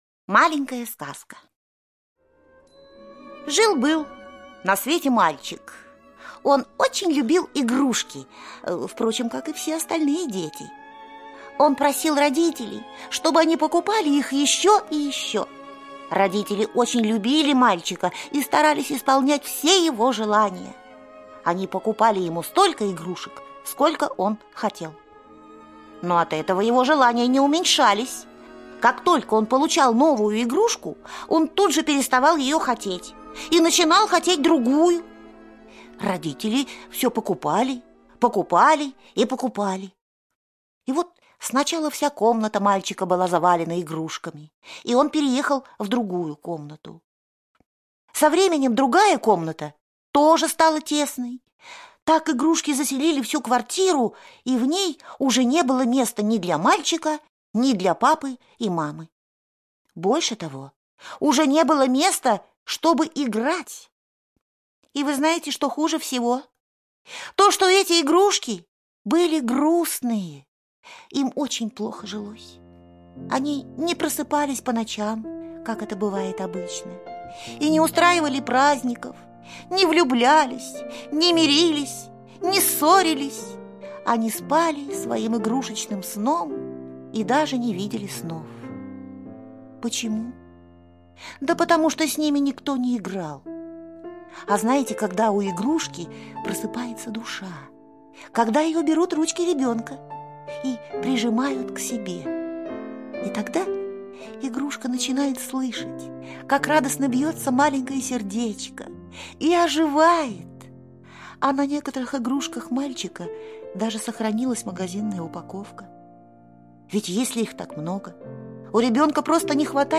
Слушайте Маленькая сказка - аудиосказка Онисимовой О. Жил на свете мальчик.